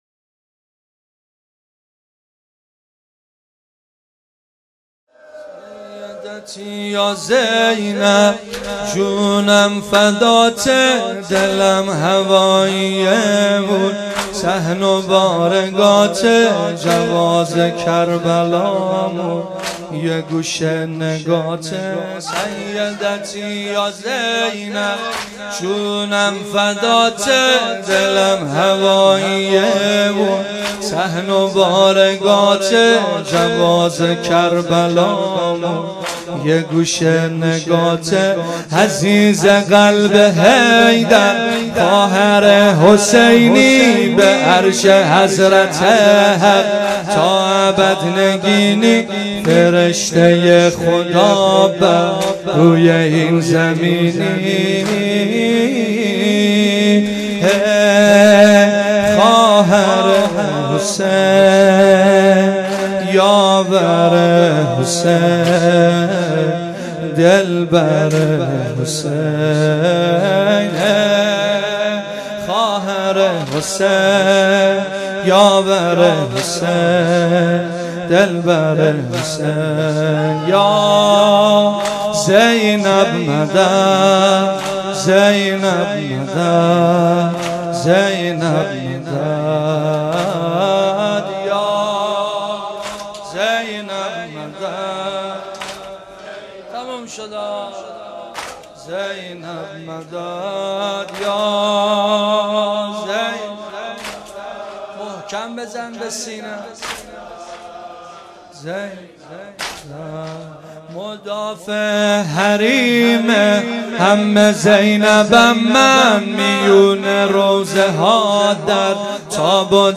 واحد | سیدتی یا زینب جونم فدات
مداحی
جلسه هفتگی